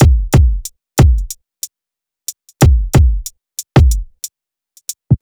HP092BEAT3-L.wav